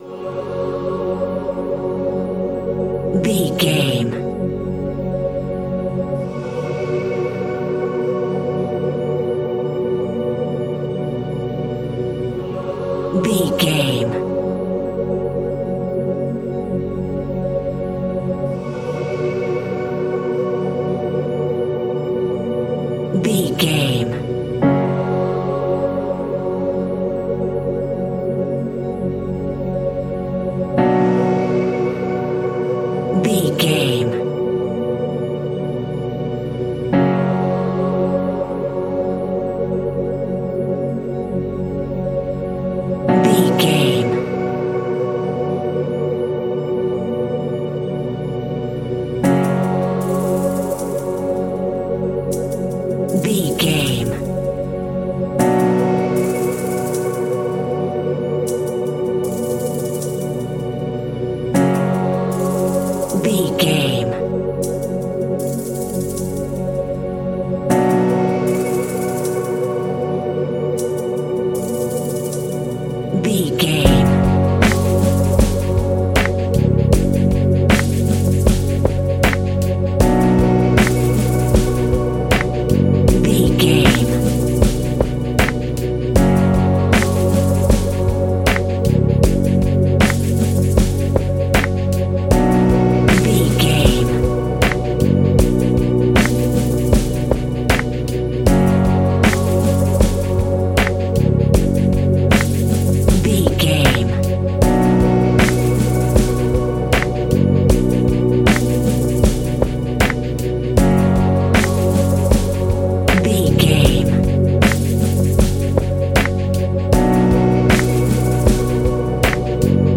Aeolian/Minor
B♭
tension
ominous
dark
suspense
haunting
eerie
synthesizer
horror
keyboards
ambience
pads
eletronic